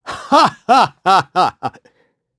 Siegfried-Vox_Happy4.wav